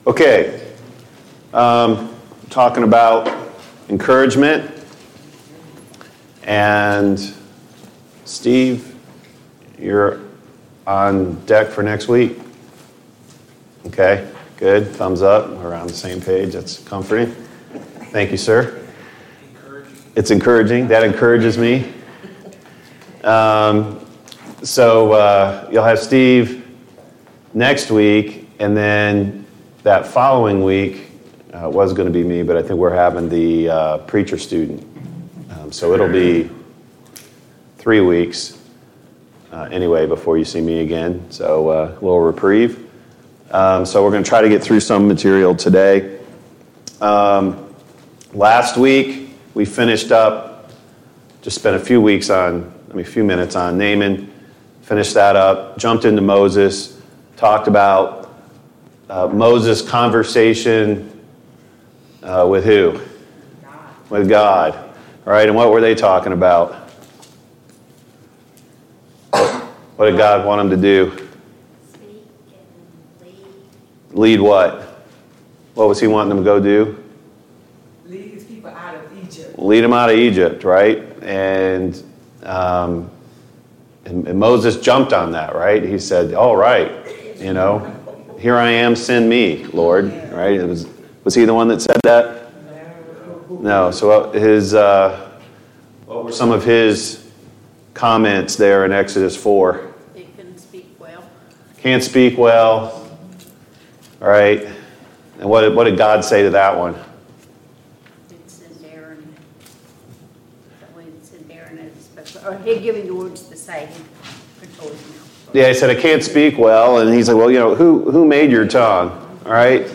Living outside of the Garden Service Type: Sunday Morning Bible Class « 74.